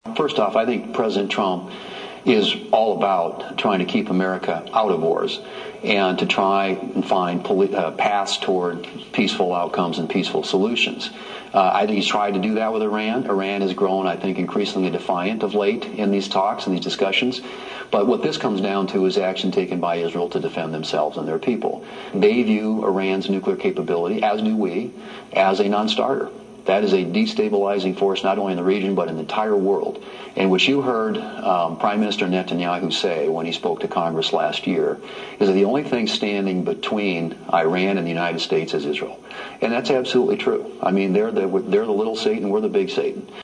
WASHINGTON, D.C.(HubCityRadio)- Fox News Sunday’s host Shannon Breem did a pre-recorded interview with U.S. Senate Majority Leader John Thune which aired on Sunday.